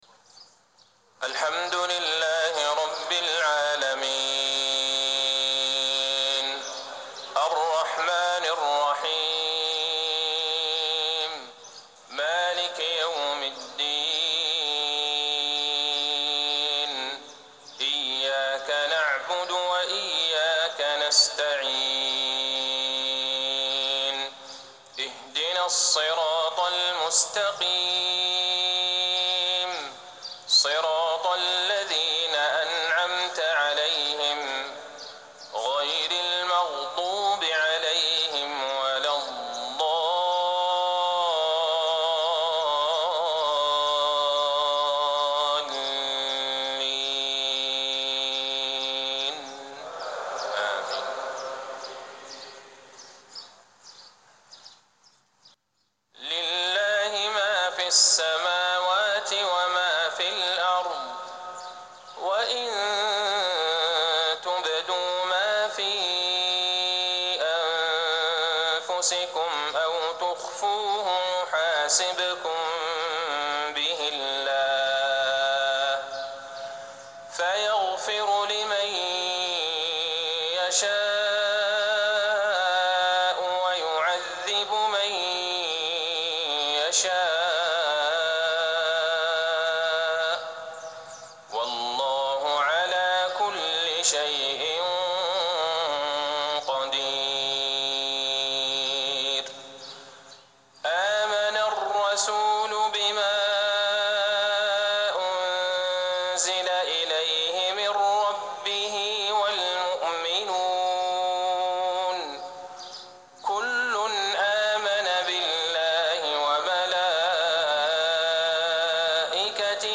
صلاة المغرب 3-6-1440هـ خواتيم سورة البقرة 284-286 | Maghreb 8-2-2019 prayer from Surah Al-Baqarah > 1440 🕌 > الفروض - تلاوات الحرمين